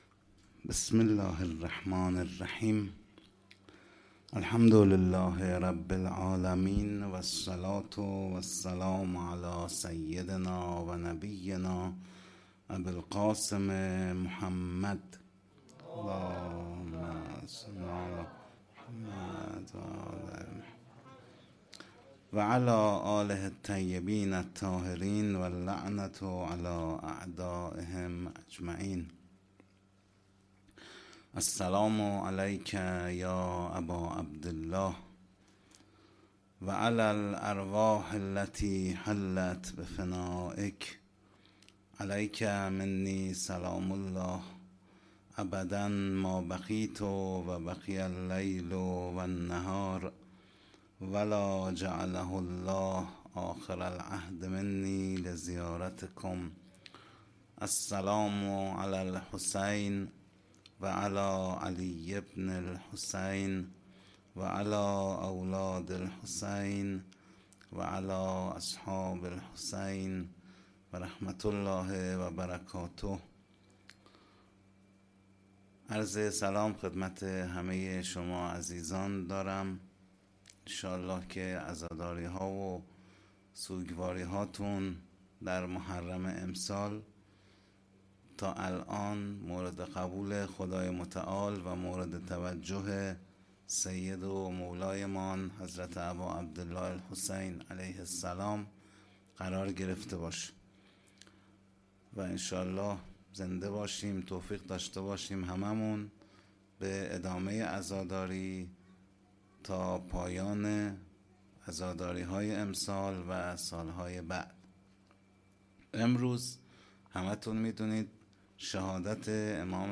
هیئت حسن جان(ع) اهواز